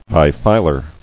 [bi·fi·lar]